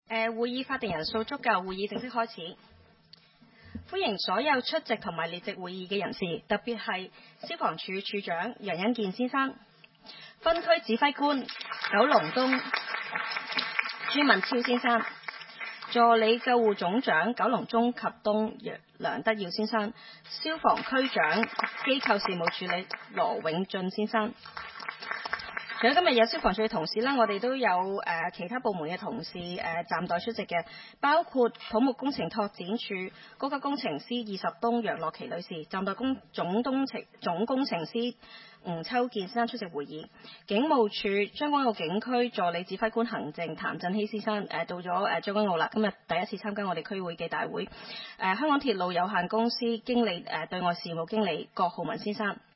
區議會大會的錄音記錄
西貢將軍澳政府綜合大樓三樓